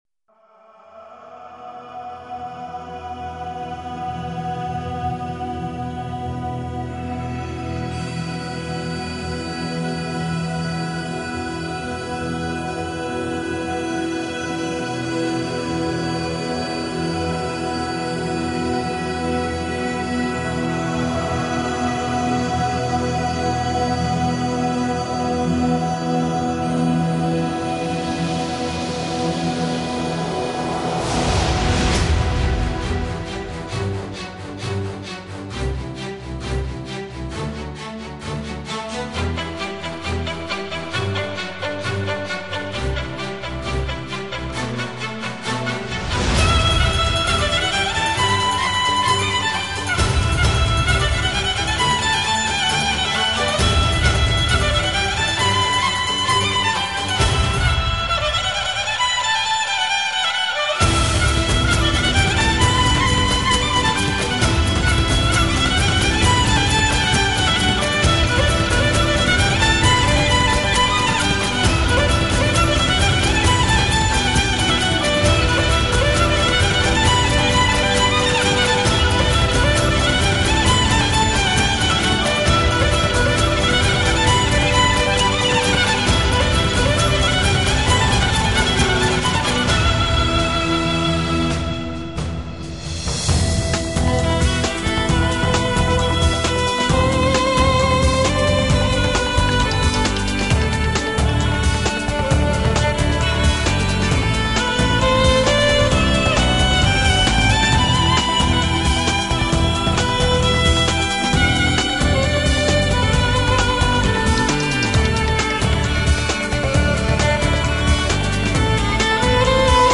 shotlandija_irlandija___skripka_volinka_udarnie_.mp3